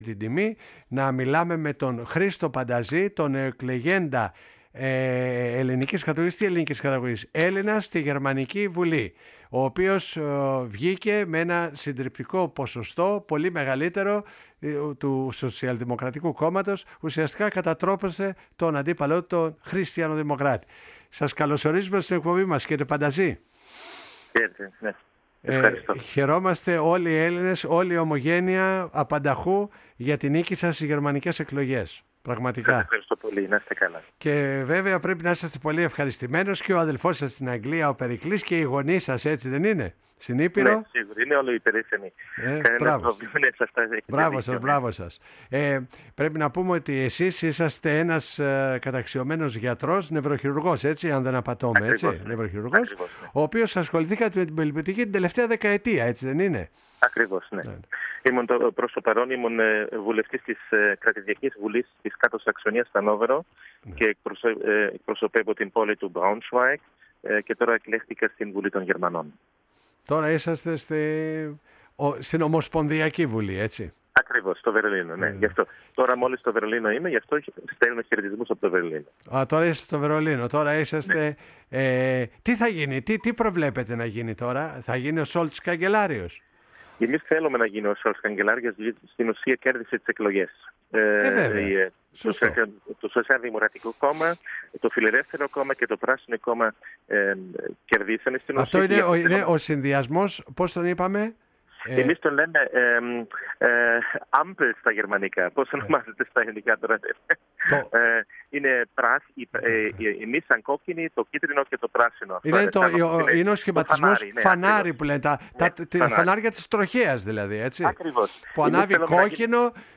Ο Χρήστος Πανταζής, ο μοναδικός ‘Ελληνας βουλευτής στη νέα Γερμανική Βουλή (με το Σοσιαλδημοκρατικό Κόμμα), δήλωσε στην εκπομπή «Η Παγκόσμια Φωνή μας» στο Ραδιόφωνο της Φωνής της Ελλάδας ότι το κόμμα του αναφέρει στο πρόγραμμά του από το 1925 ότι είναι υπέρ της Ενιαίας Ευρωπαϊκής ¨Ενωσης.